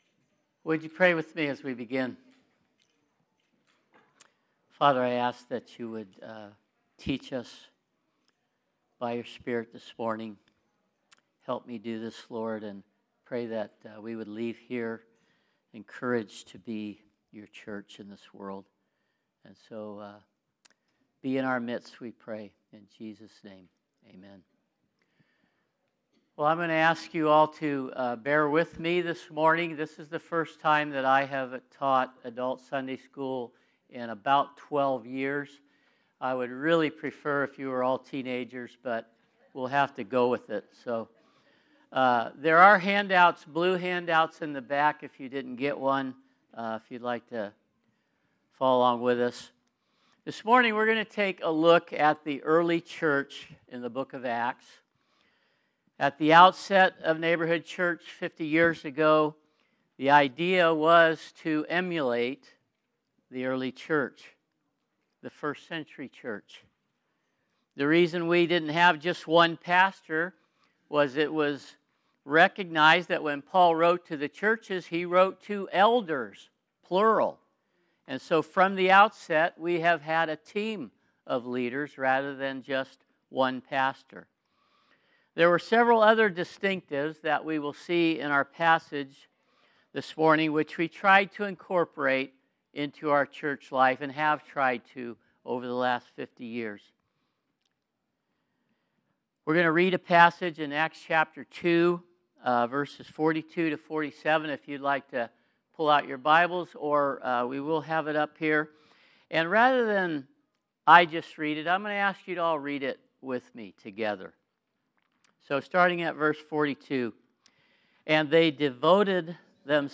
Type: Special Event, Sunday School